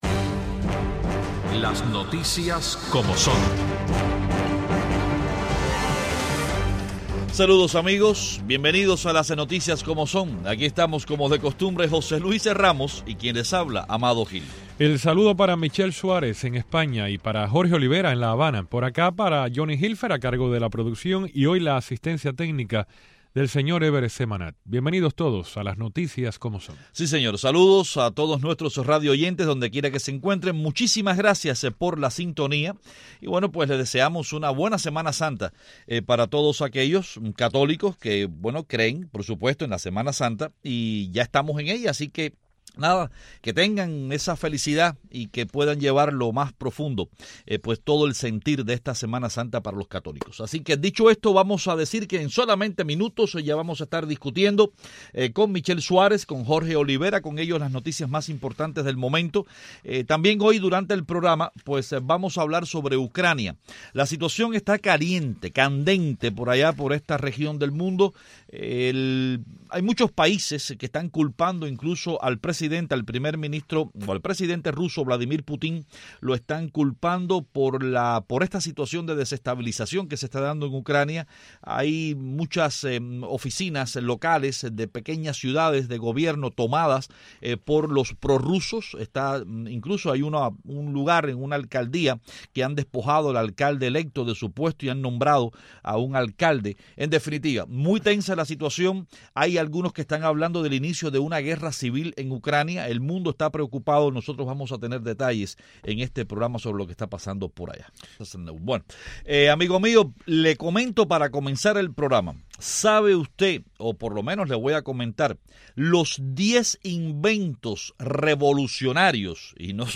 Los periodistas